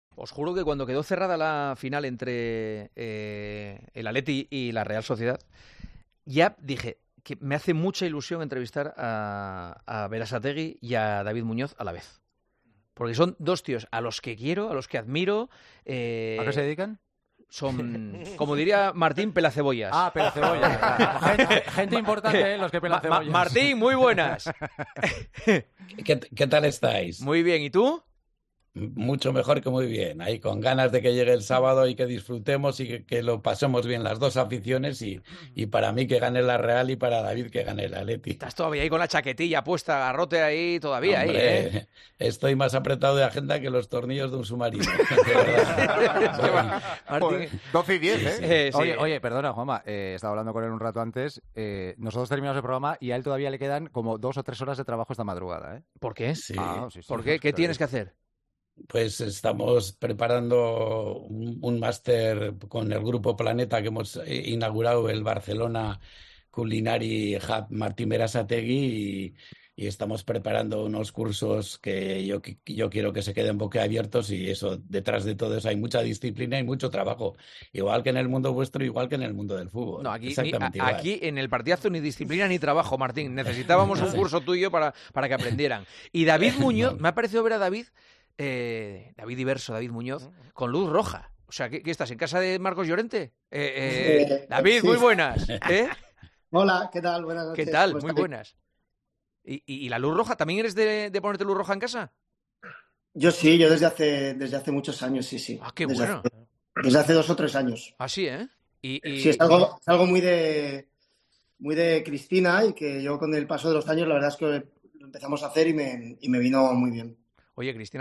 La revelación tuvo lugar en una edición especial del programa, donde Muñoz compartía una charla con el también chef Martín Berasategui en la previa de la final de la Copa del Rey.